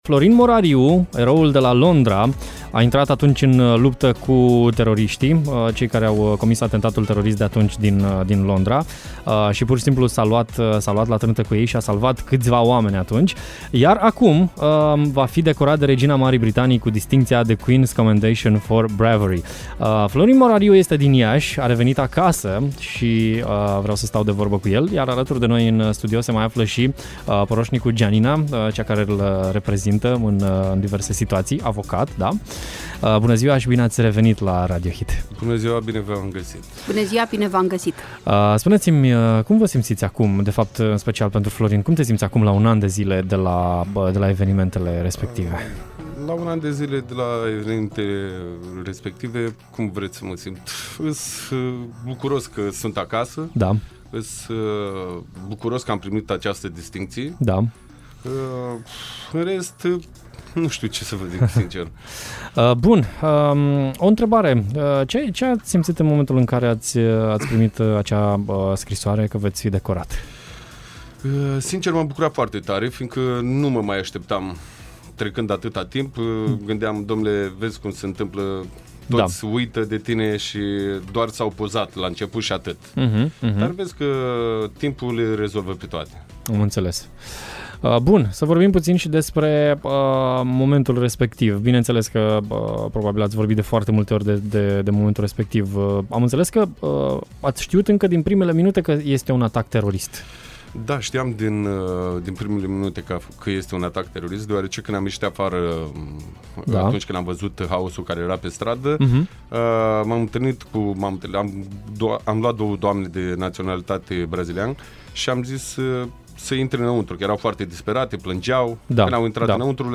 în direct la Radio Hit